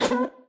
Media:RA_Dragon_Chicken_atk_clean_005.wav 攻击音效 atk 局内攻击音效
RA_Dragon_Chicken_atk_clean_001.wav